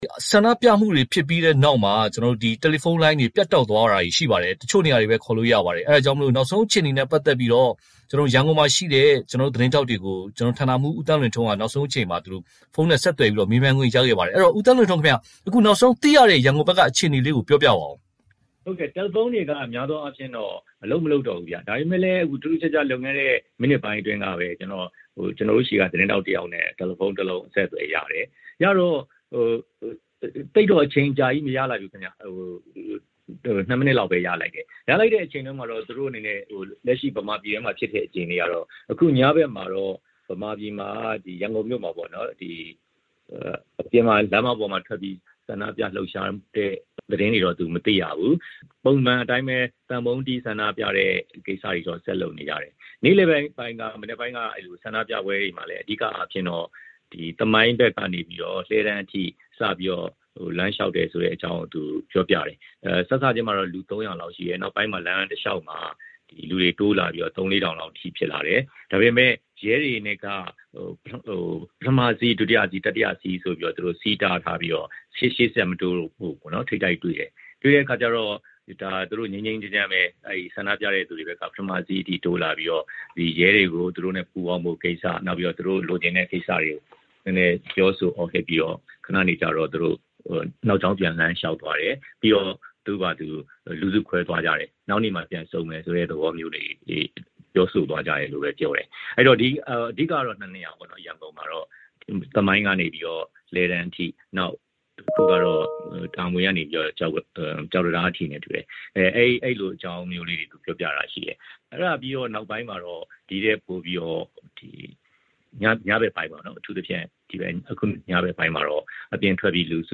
မြန်မာပြည်နဲ့ တယ်လီဖုံးအဆက်အသွယ်